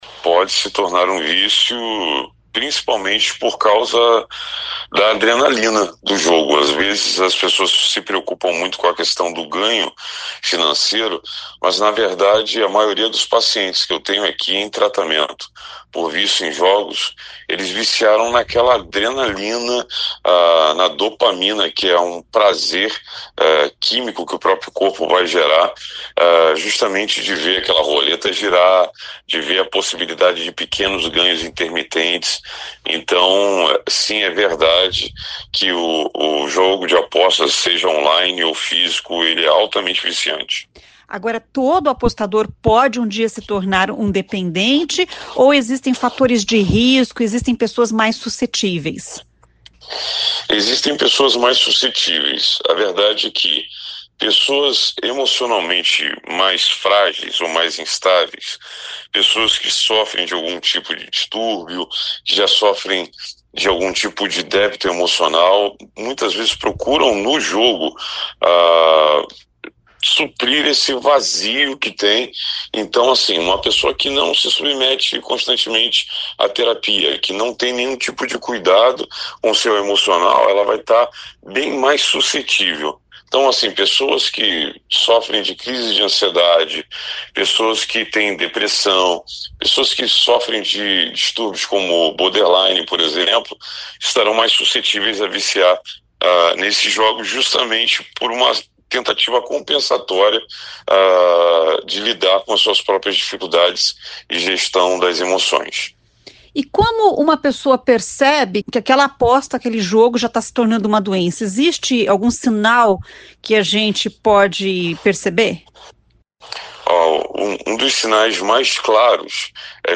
Os jogos online são viciantes por causa da adrenalina lançada no organismo a partir do estímulo cerebral. Quando a pessoa joga todo dia e tem até um ritual de jogatina é sinal que vício está instalado. Ouça a entrevista: